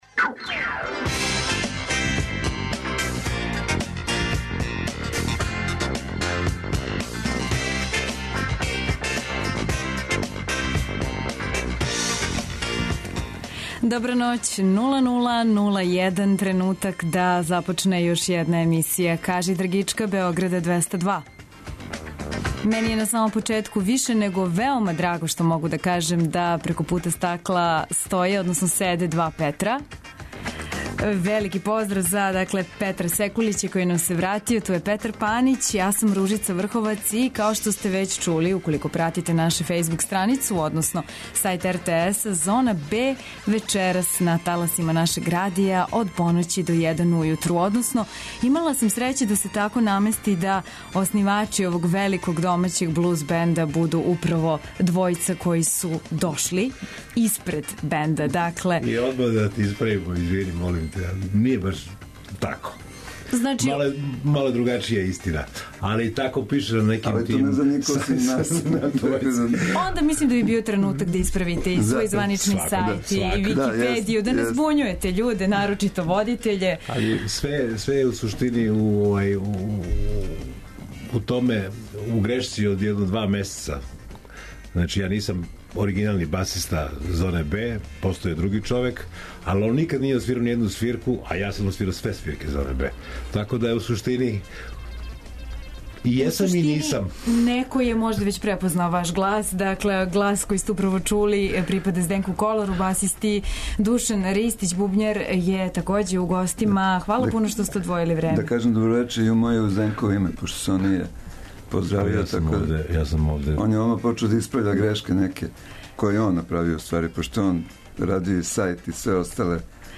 У госте нам долазе блузери из чувеног домаћег састава "Зона Б". Нема много домаћих бендова који свирају и стварају овакву врсту музике, а који су, при том, дискографски и концертно активни.